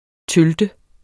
Udtale [ ˈtœldə ]